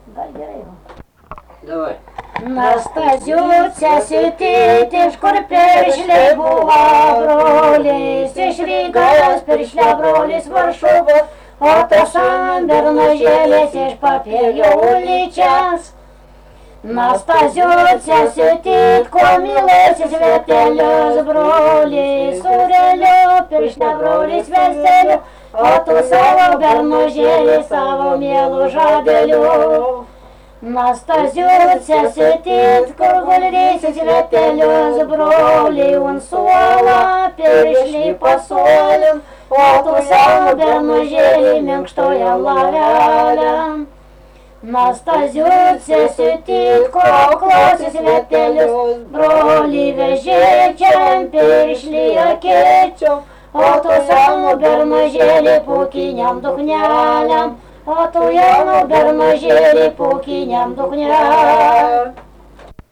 daina
Rageliai
vokalinis